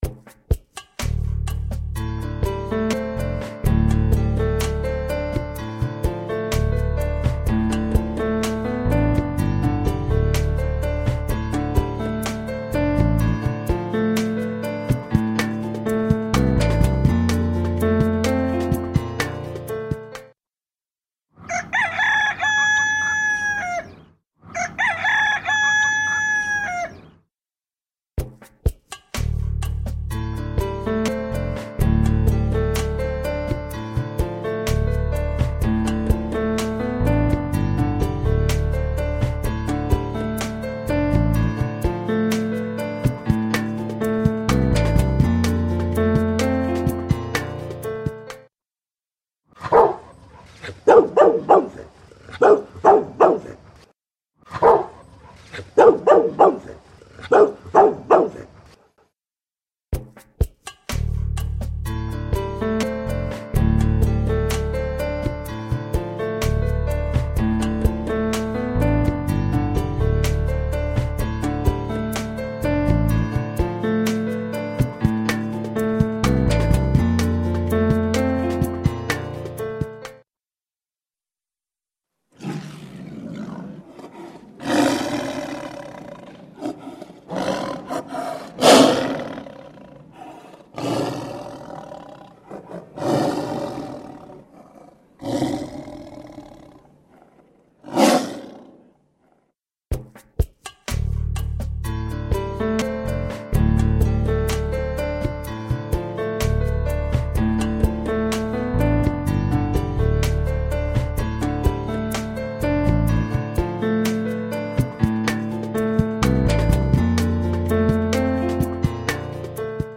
Per realitzar el joc he grabat a l’audacity una música i de tant en tant sona el so d’un animal diferent: un gos, un gall, uns ocells,…i un parell de cops s’escoltarà el so del tigre.